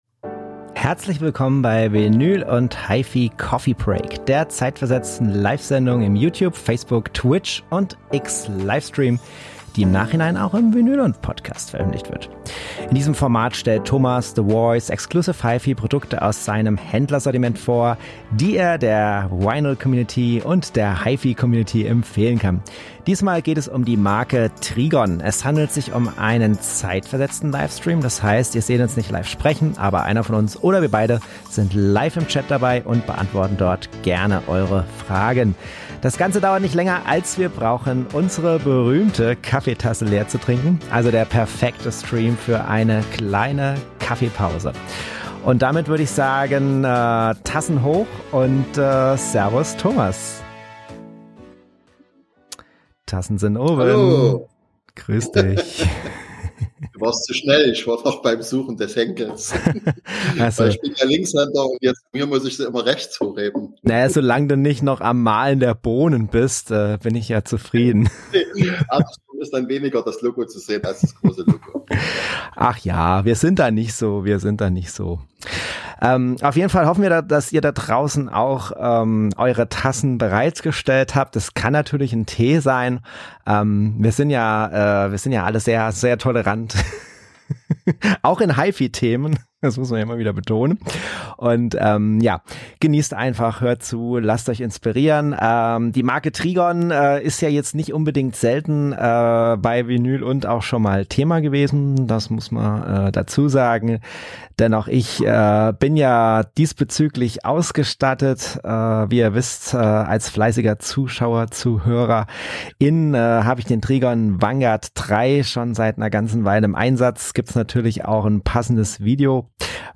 Diesmal geht es um die Marke Trigon. Es handelt sich um einen zeitversetzten Livestream, das heißt, ihr seht uns nicht live sprechen, aber einer von uns oder wir beide sind live im Chat dabei und beantworten dort gerne eure Fragen.